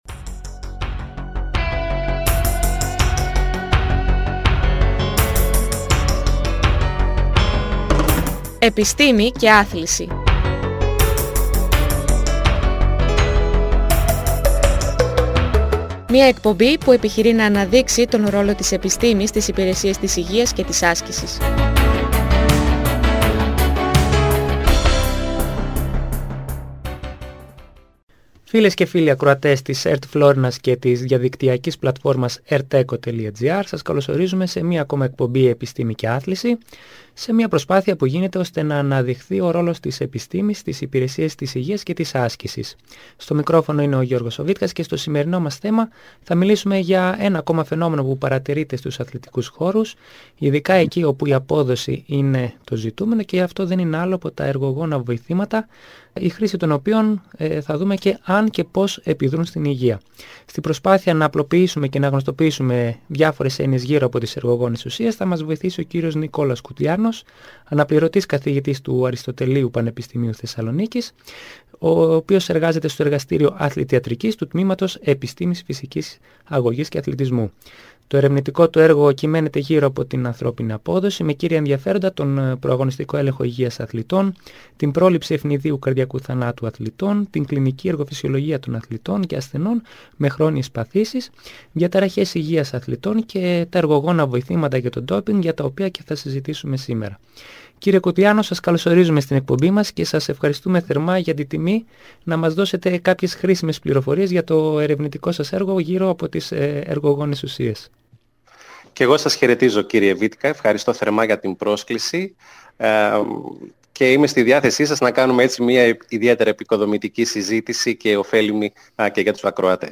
Μια εκπαιδευτική εκπομπή όπου διακεκριμένοι καθηγητές και επιστήμονες, αναδεικνύουν τον ρόλο της επιστήμης στις υπηρεσίες της υγείας και της άσκησης. Σκοπός είναι να διευκρινιστούν και να απλοποιηθούν διάφορες έννοιες γνωστές και άγνωστες, που μπορούν να ωφελήσουν όλους όσους ασκούνται, να παροτρύνουν όσους απέχουν, να ξεκινήσουν την άσκηση και να δώσουν πολύτιμες πληροφορίες και συμβουλές σε όσους καθοδηγούν ασκούμενους, όπως είναι οι προπονητές, οι γυμναστές και άλλοι φορείς της άσκησης.